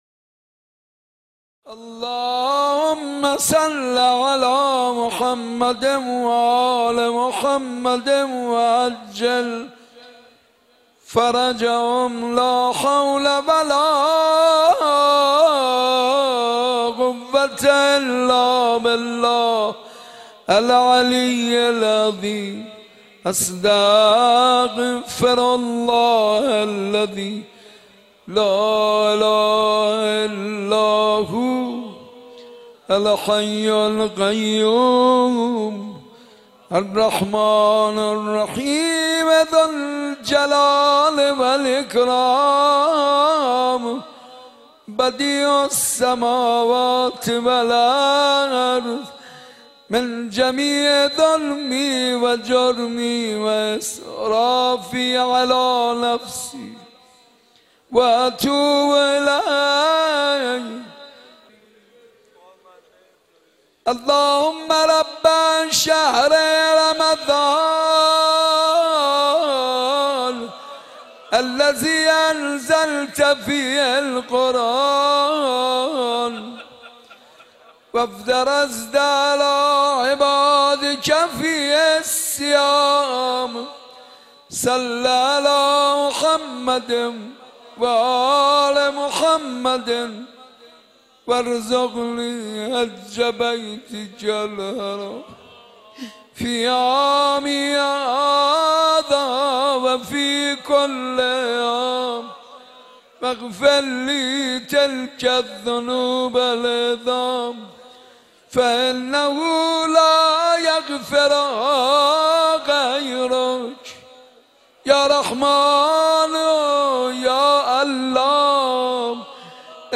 در مسجد ارک برگزار شد